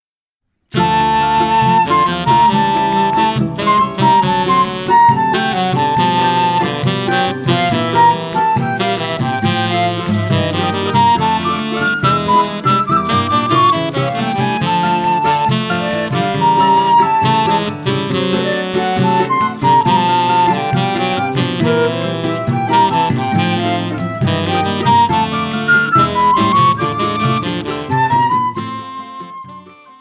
– áudio completo com regional, solo e contraponto.
flauta
saxofone tenor